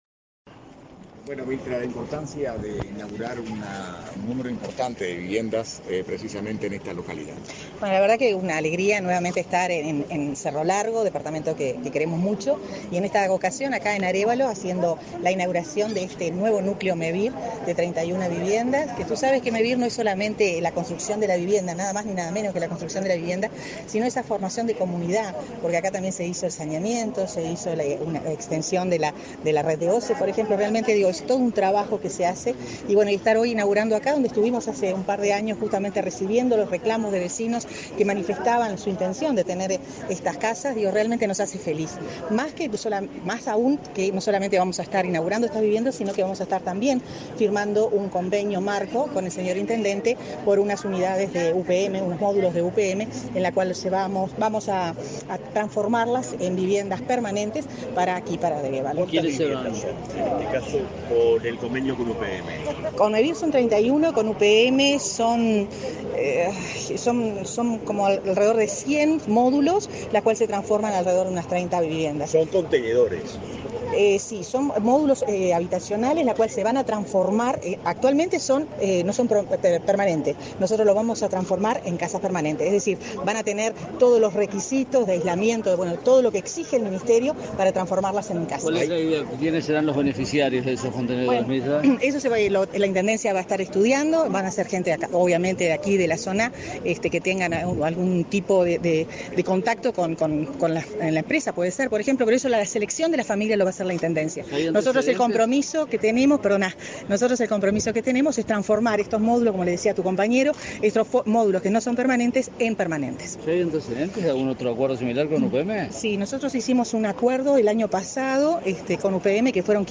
Declaraciones a la prensa de la ministra de Vivienda, Irene Moreira
Tras la celebración, la titular del Ministerio de Vivienda y Ordenamiento Territorial realizó declaraciones a la prensa.